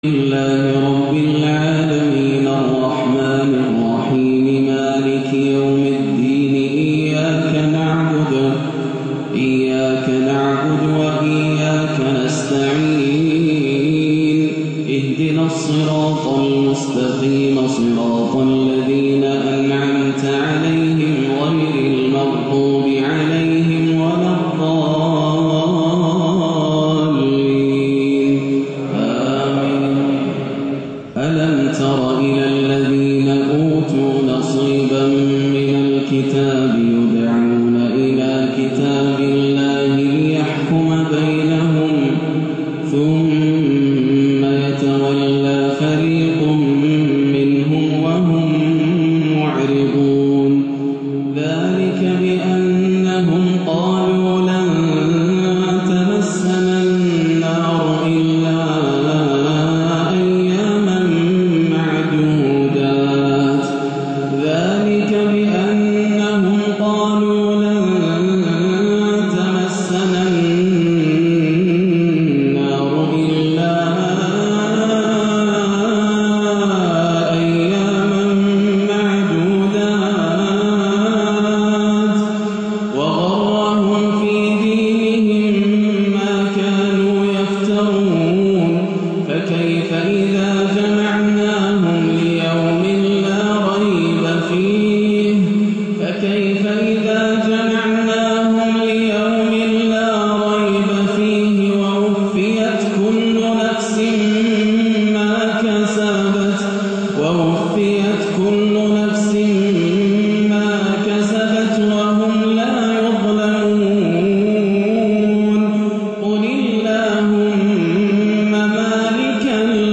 (قُلِ اللَّهُمَّ مَالِكَ الْمُلْكِ) أسلوب جديد وعجيب في هذه التلاوة من سورة آل عمران - 19-7 > عام 1437 > الفروض - تلاوات ياسر الدوسري